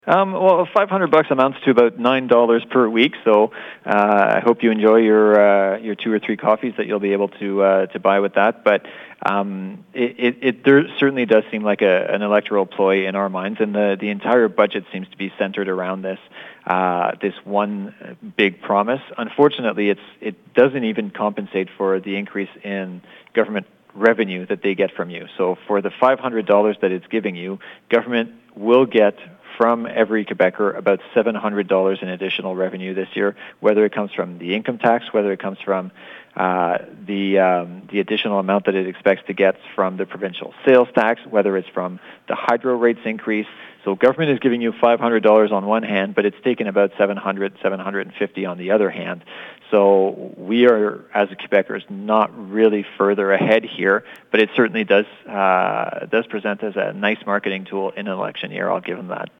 Pontiac MNA André Fortin spoke with CHIP 101.9 about some of the highlights (and lowlights) of this year’s provincial budget, which was tabled last week.